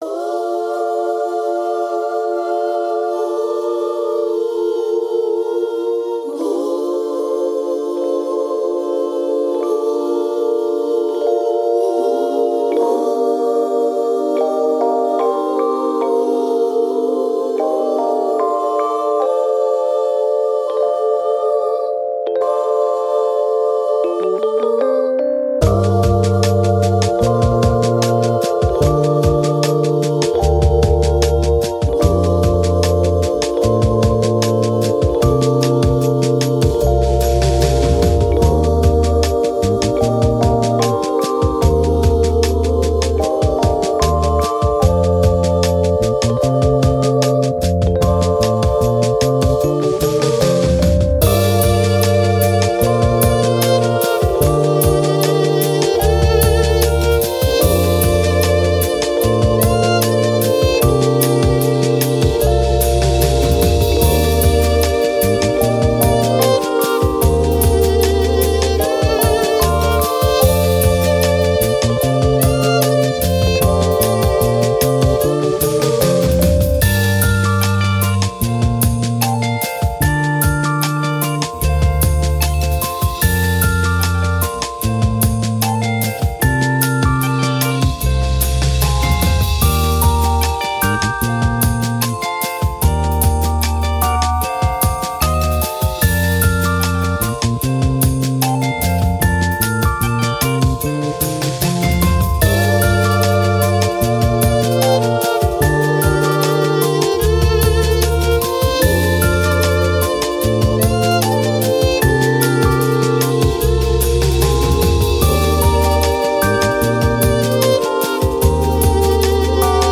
Soul, Hip Hop, Vocal, Strings, Choir